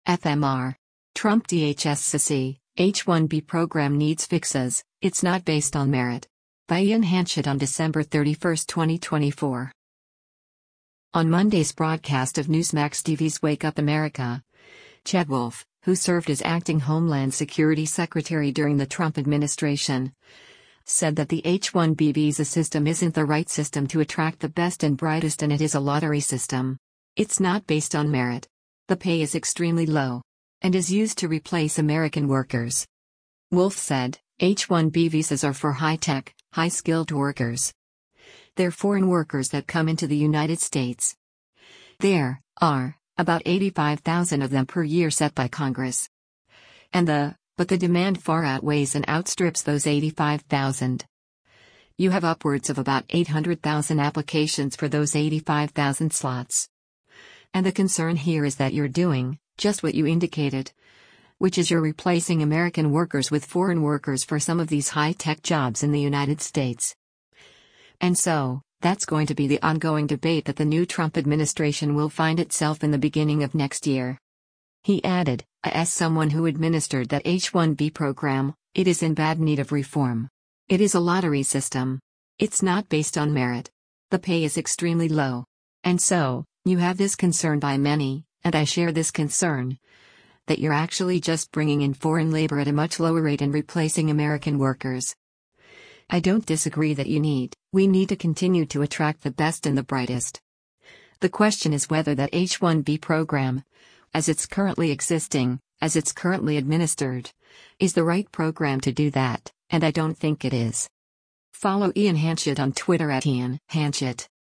On Monday’s broadcast of Newsmax TV’s “Wake Up America,” Chad Wolf, who served as acting Homeland Security Secretary during the Trump administration, said that the H-1B visa system isn’t the right system to attract the best and brightest and “It is a lottery system.